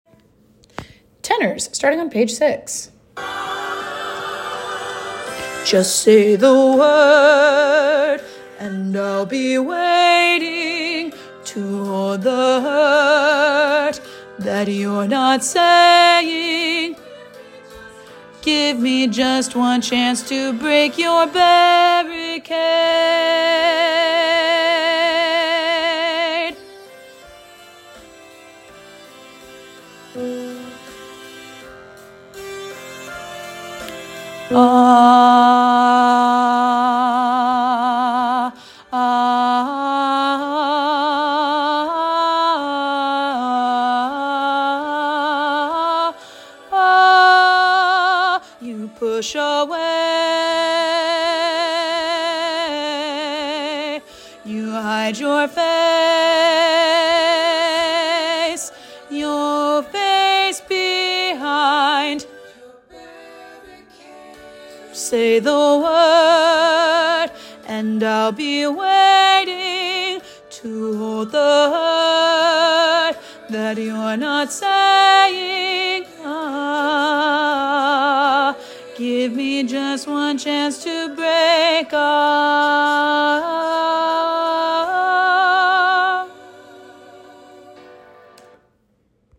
with lush harmonies, tall chords, and an epic conclusion.
Tenor